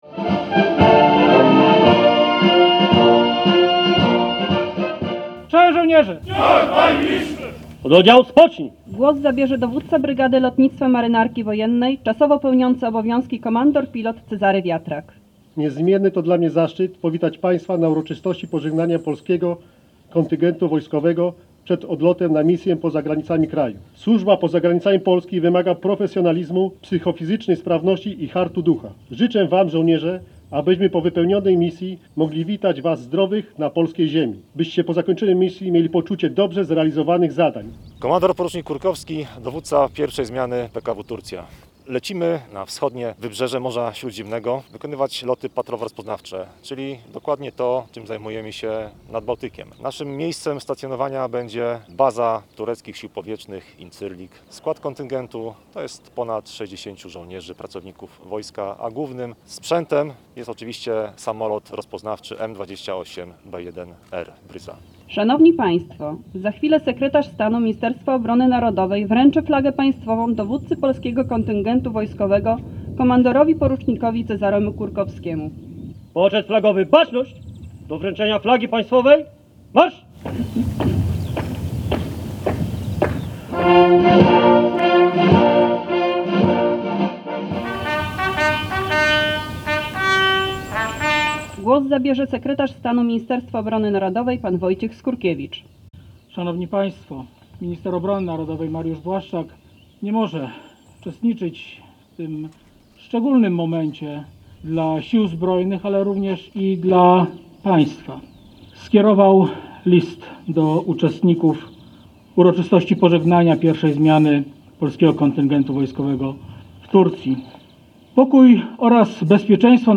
Polscy żołnierze wylecieli na misję do Turcji. We wtorek w 43. Bazie Lotnictwa Morskiego odbyło się uroczyste pożegnanie.
Ze względu na epidemię, wydarzenie odbyło się bez udziału ich rodzin.